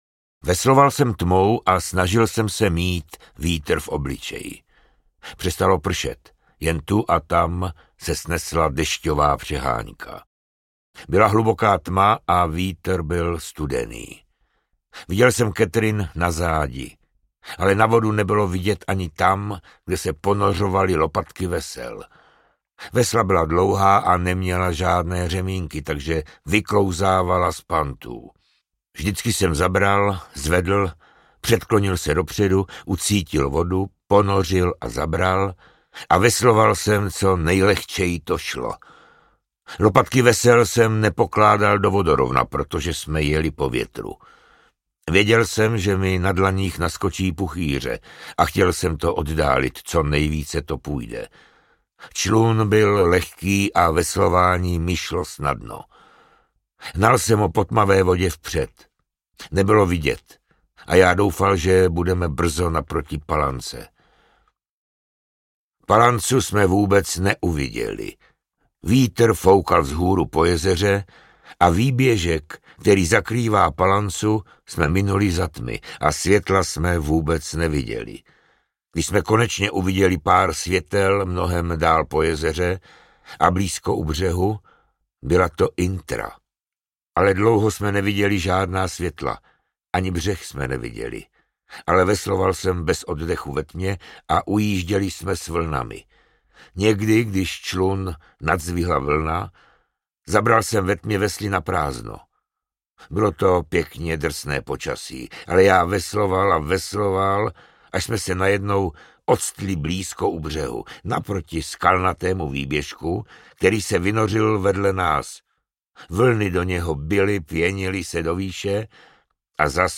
Sbohem, armádo! audiokniha
Ukázka z knihy
Vyrobilo studio Soundguru.